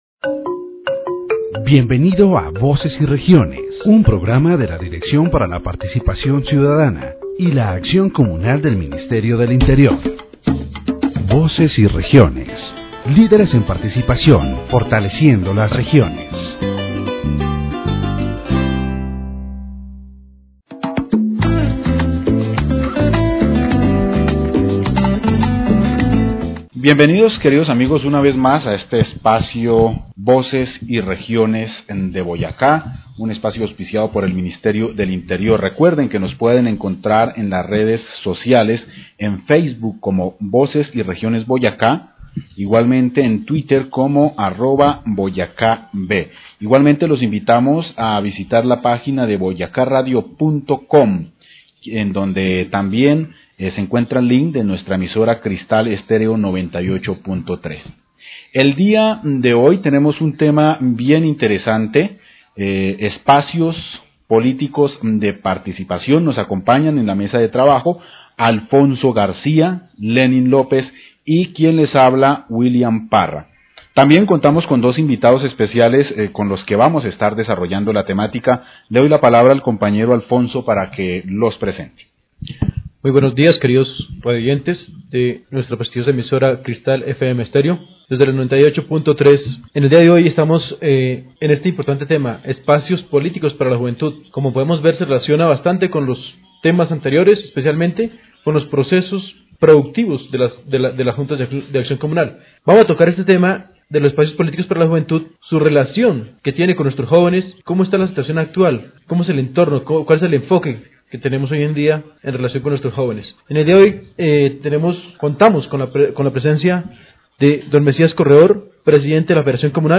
In a broadcast of the radio program “Voces y Regiones” of Boyacá, sponsored by the Ministry of the Interior, the topic of political spaces for youth in Colombia was addressed.